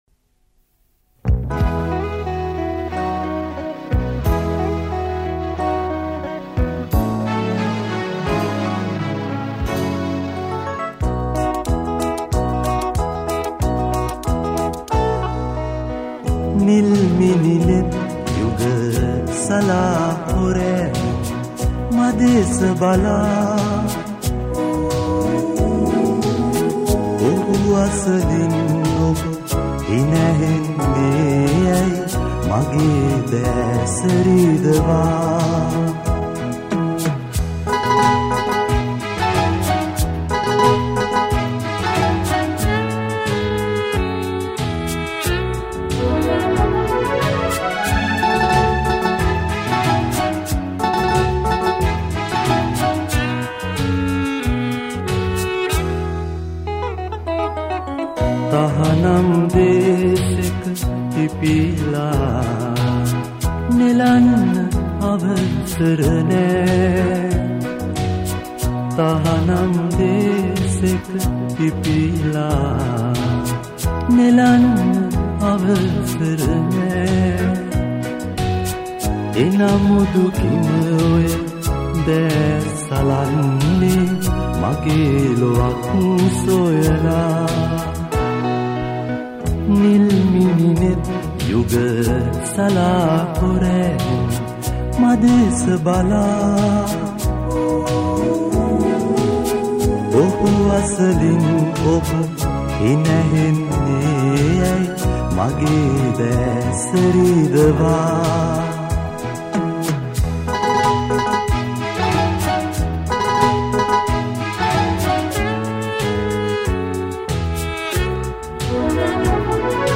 All these songs were recorded (or remastered) in Australia.